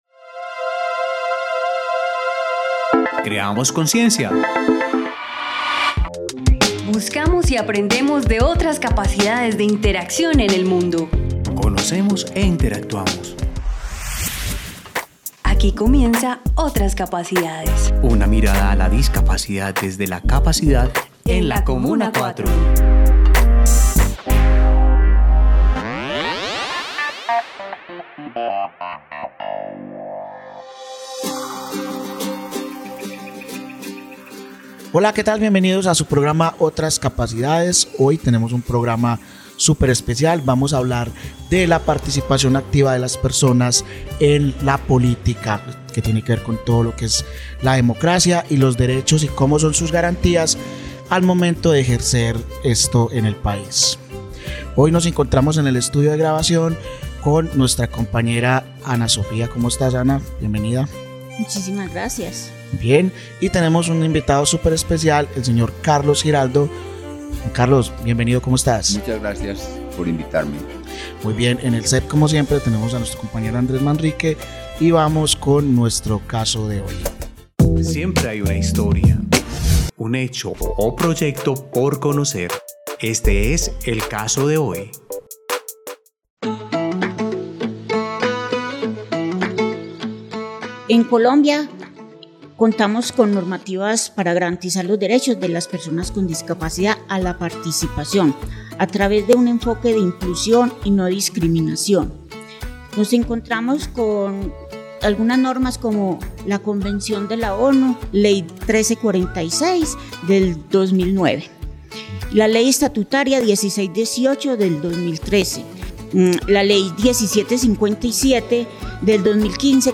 👉 Y es por eso que nos preguntamos: 🤔 ¿Las personas con discapacidad están participando activamente en la vida política de nuestra ciudad? 🗣 A través de esta conversación, reflexionamos sobre los distintos roles que asumen las personas con discapacidad en la sociedad, demostrando que no hay límites cuando se trata de alzar la voz, incidir y transformar realidades.